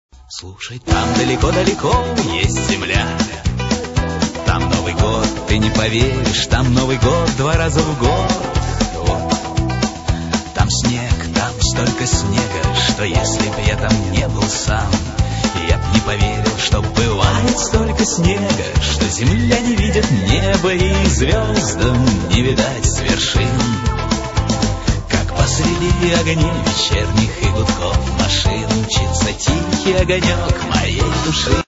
rock музыка